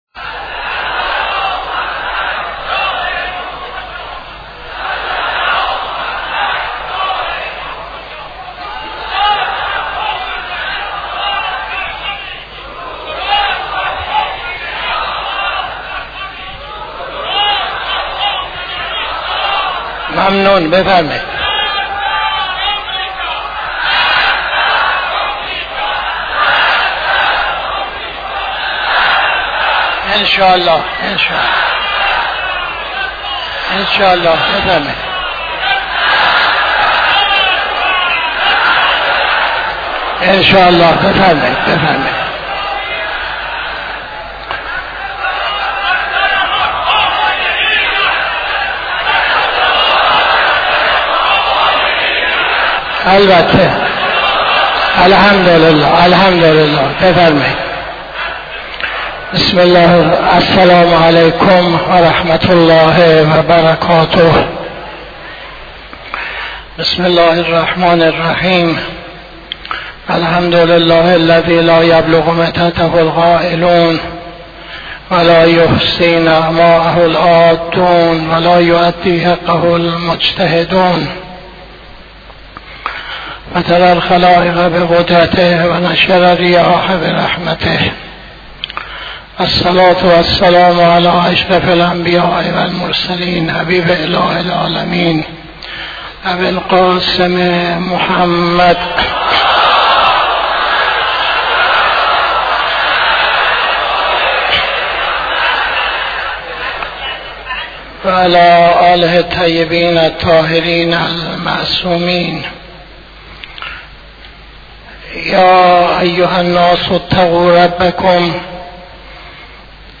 خطبه اول نماز جمعه 12-10-82